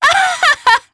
Demia-Vox_Happy3_jp.wav